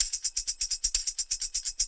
Index of /90_sSampleCDs/Houseworx/02 Percussion Loops